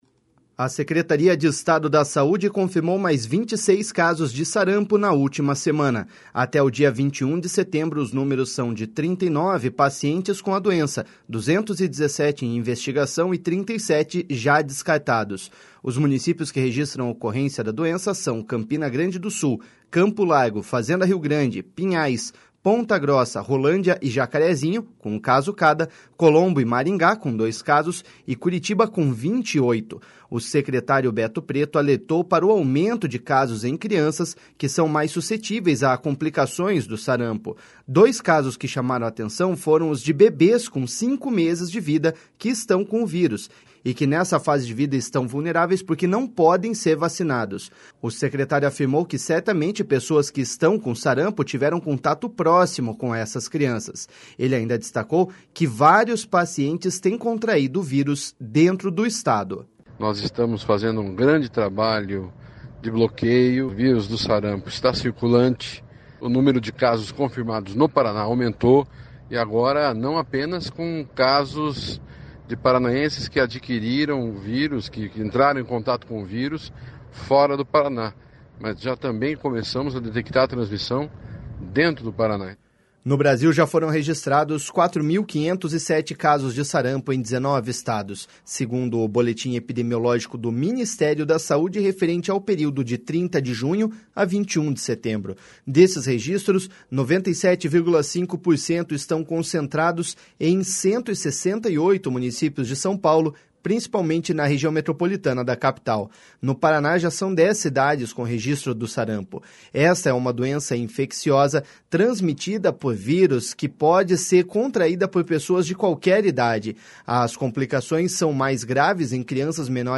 Ele ainda destacou que vários pacientes têm contraído o vírus dentro do Estado.// SONORA BETO PRETO.//